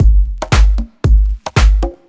• deep bass tech kick loop.wav
deep_bass_tech_kick_loop_phs.wav